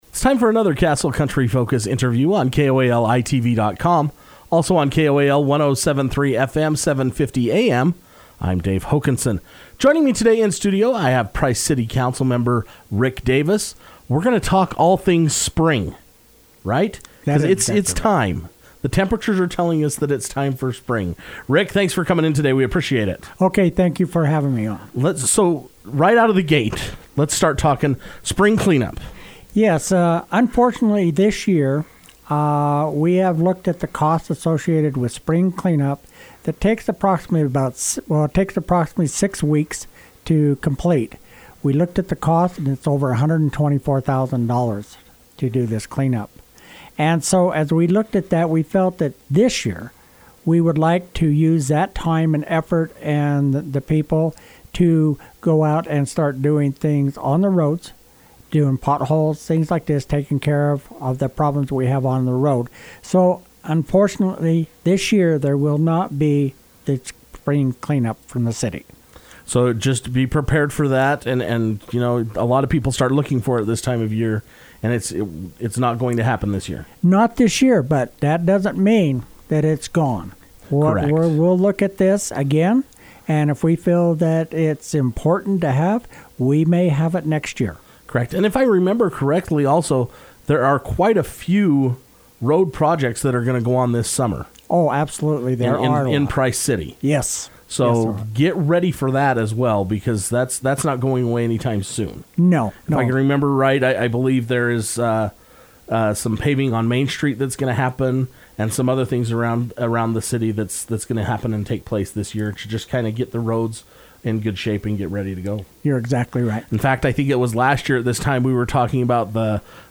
The Price City update caught up with Council Member Rick Davis who spoke about this year’s decision on spring cleanup and the St. Patrick’s Day Parade.